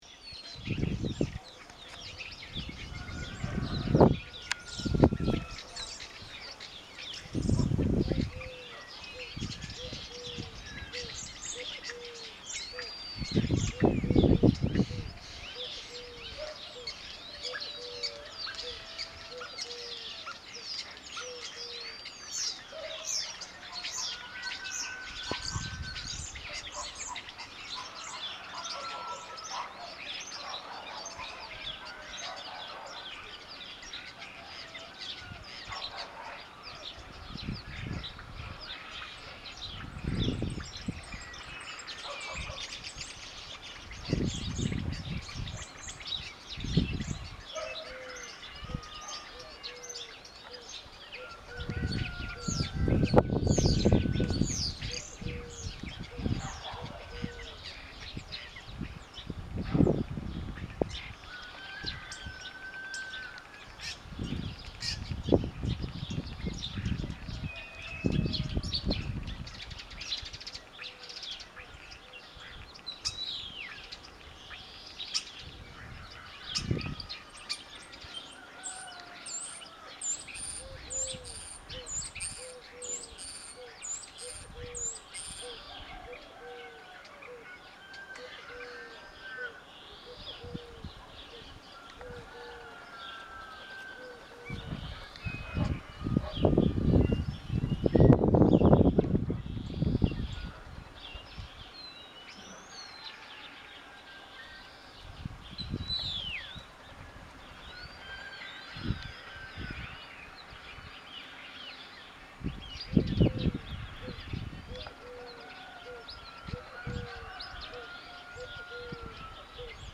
Sturnus vulgaris vulgaris
field recording